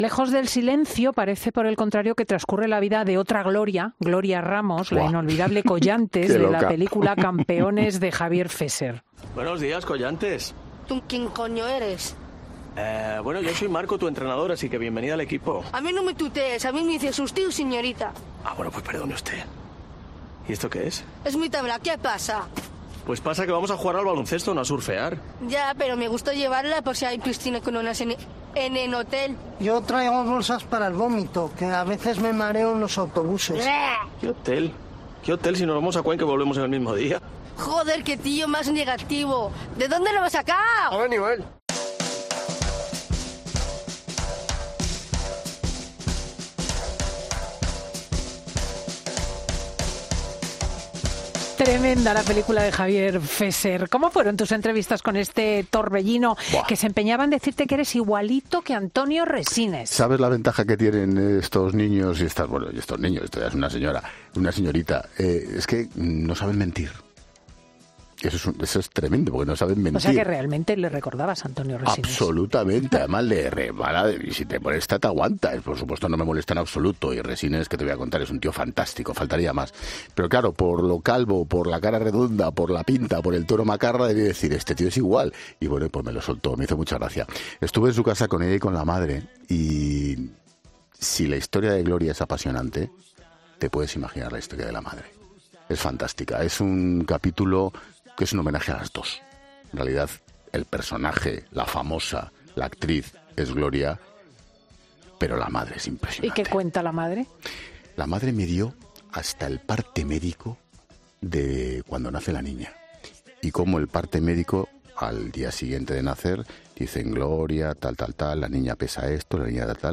El comunicador y director de 'La Linterna' ha pasado por los micrófonos de 'Fin de Semana' para contarnos los entresijos de su último libro